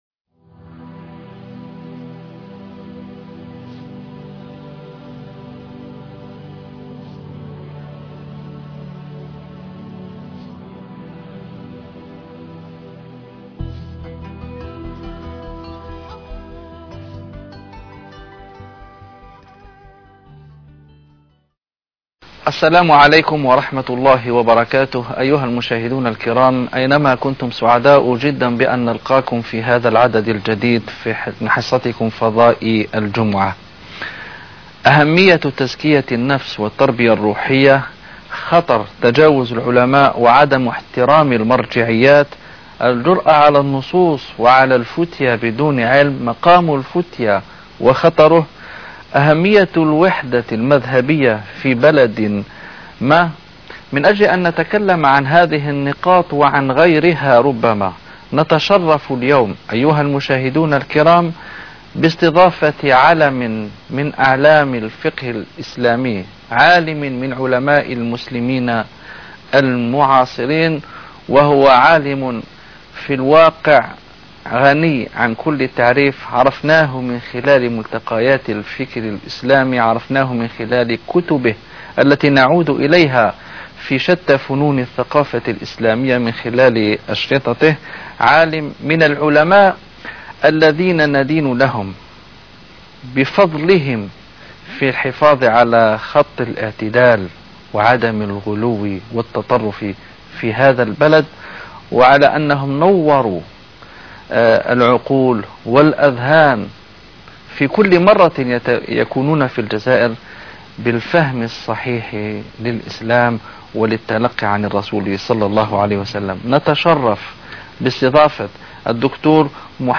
A MARTYR SCHOLAR: IMAM MUHAMMAD SAEED RAMADAN AL-BOUTI - الدروس العلمية - محاضرات متفرقة في مناسبات مختلفة - ندوة تلفزيونية بعنوان : كيف يكون الانتماء إلى السلف ؟